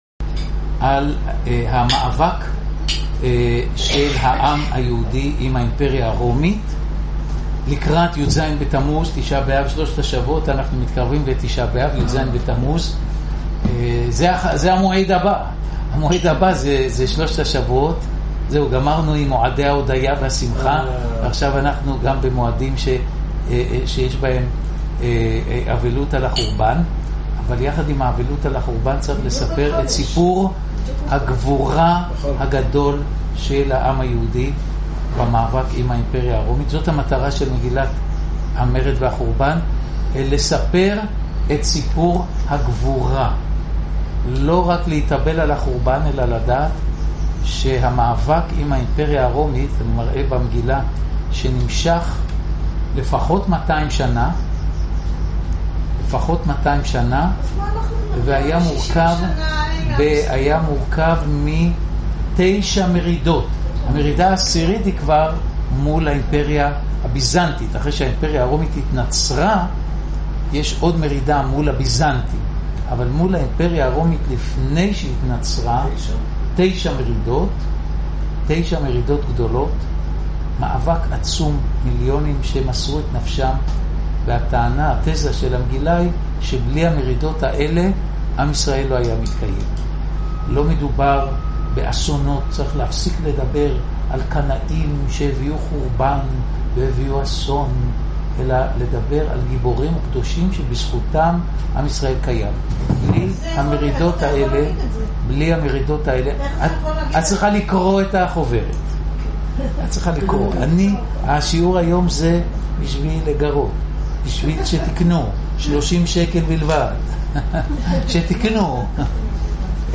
מתחילים את הערב עם לימוד קצר על משה רבנו, שעמד על הר נבו, הנמצא מול בית חגלה מעבר הירדן. הלימוד על ספר שופטים מתחיל 12 וחצי דקות אחרי תחילת הערב.